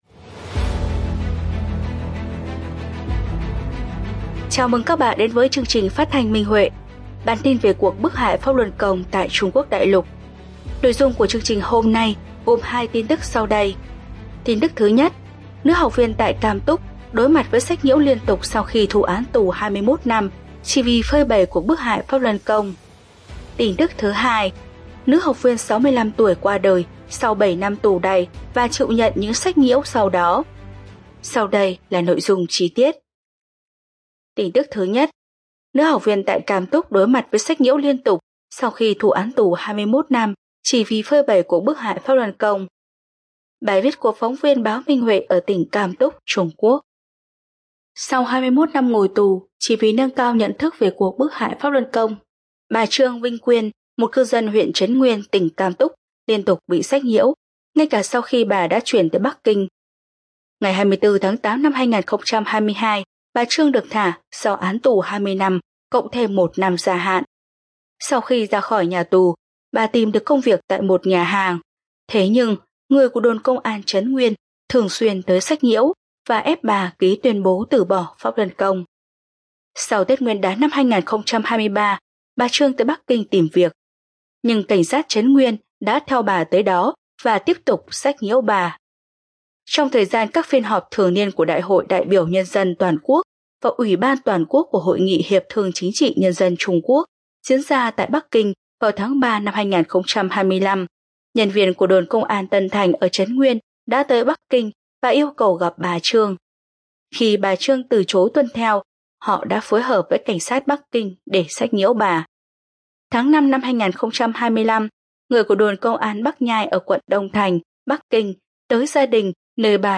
Chương trình phát thanh số 222: Tin tức Pháp Luân Đại Pháp tại Đại Lục – Ngày 8/7/2025